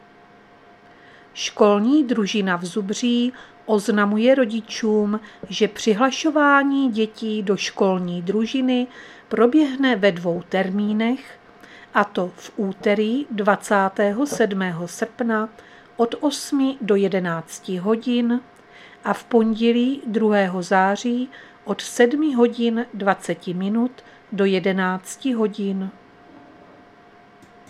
Záznam hlášení místního rozhlasu 22.8.2024